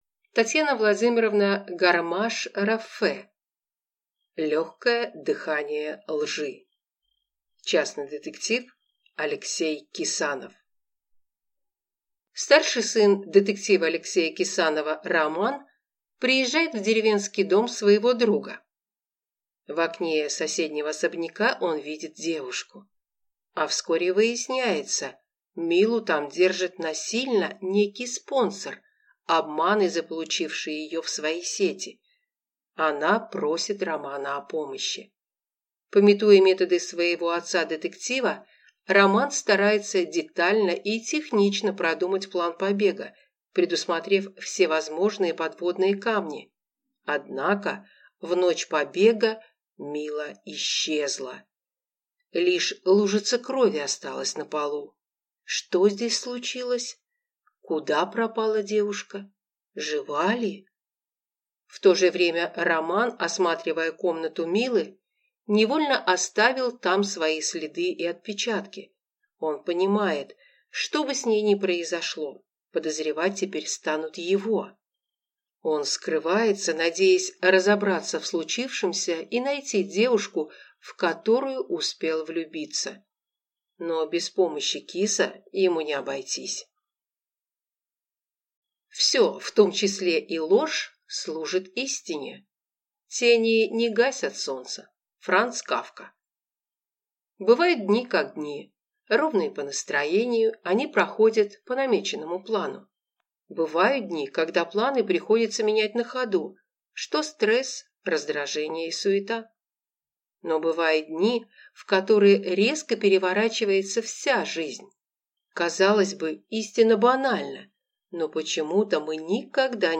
Аудиокнига Легкое дыхание лжи | Библиотека аудиокниг
Прослушать и бесплатно скачать фрагмент аудиокниги